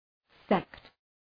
Προφορά
{sekt}
sect.mp3